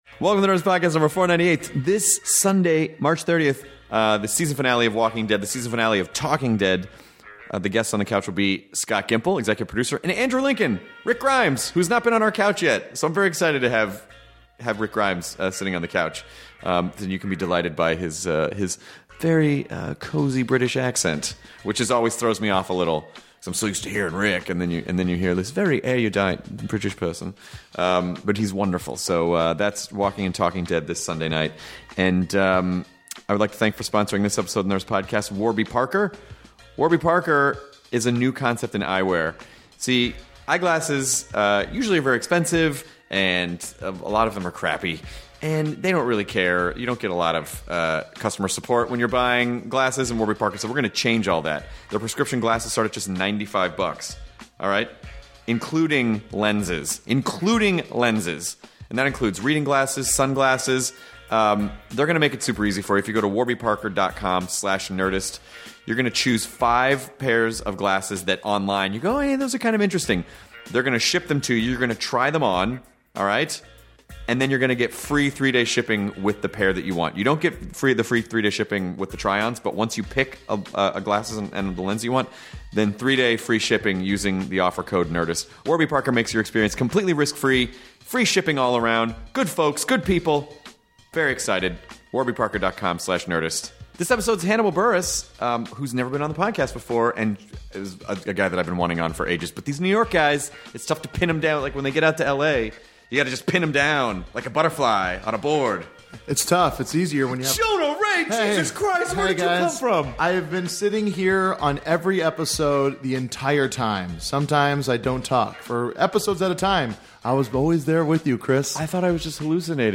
Hilarious comedian Hannibal Buress sits down with Chris and Jonah to talk about freaking out while high, performing in clubs versus theaters, creative ways to react when a joke bombs and what he wants to do outside of stand up!